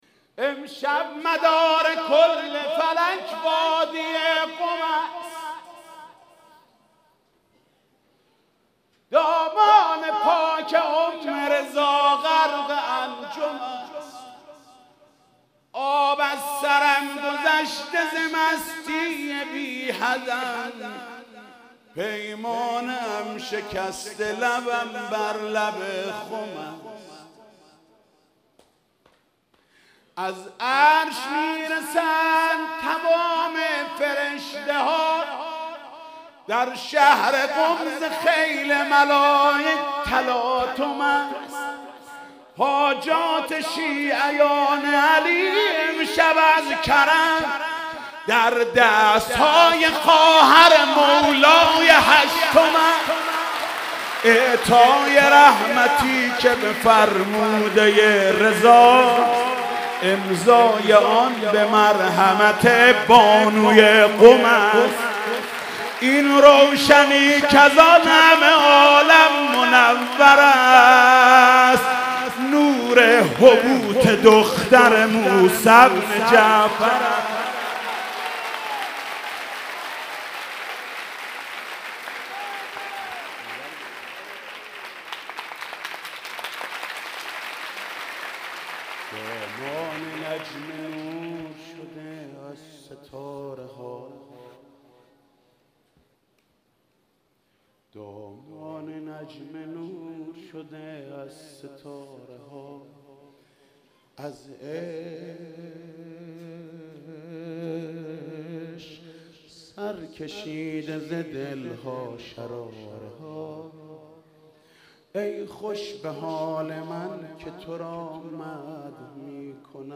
مدح: امشب مدار کل فلک وادی قم است